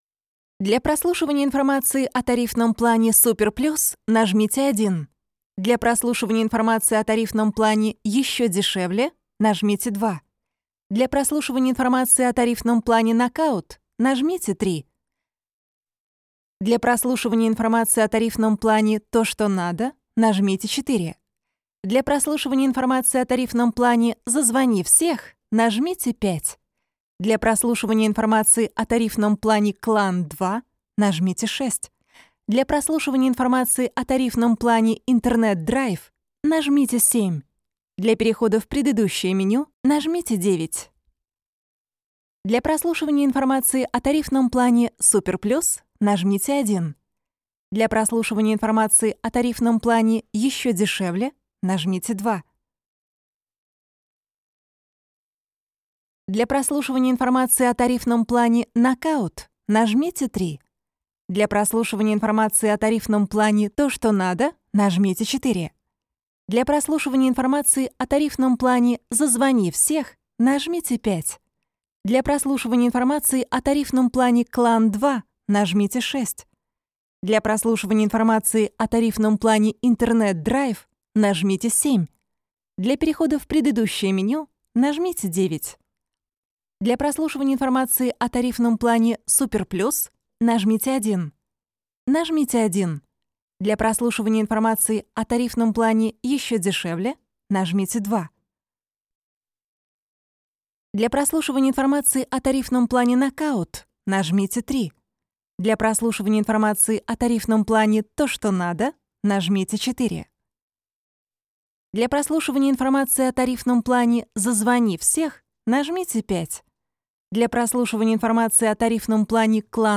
начитка – теле2